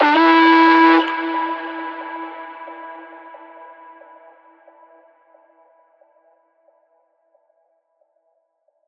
VR_vox_hit_howl_E.wav